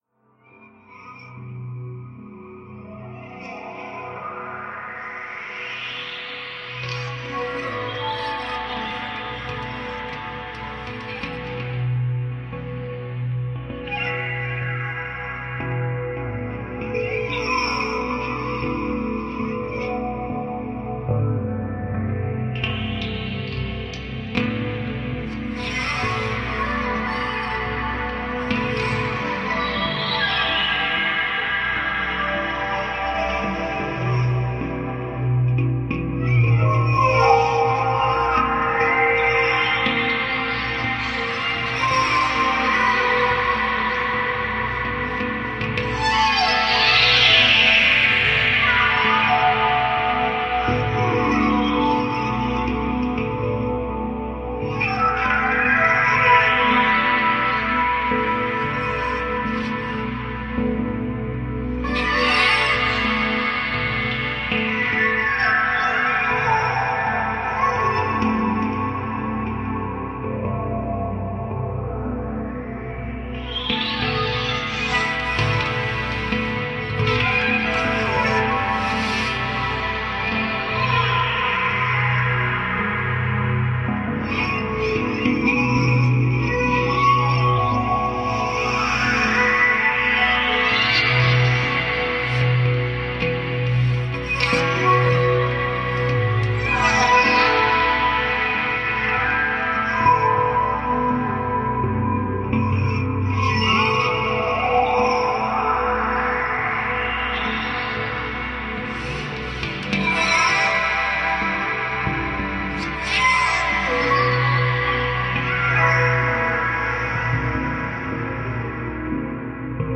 Reimagined sound of a swing in a restaurant garden, Nantes, France.